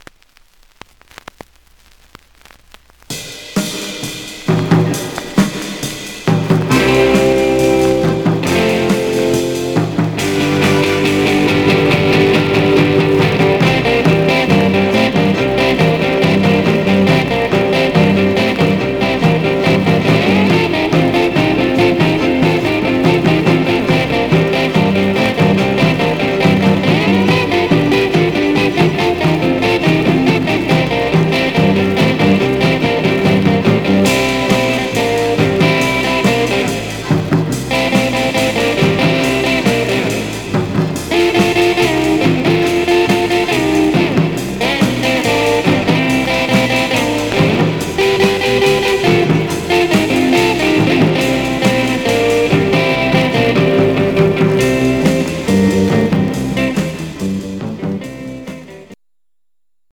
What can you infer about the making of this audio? Much surface noise/wear Mono